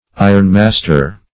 Search Result for " ironmaster" : The Collaborative International Dictionary of English v.0.48: Ironmaster \I"ron*mas`ter\, n. A manufacturer of iron, or large dealer therein.